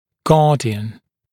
[‘gɑːdɪən][‘га:диэн]опекун, попечитель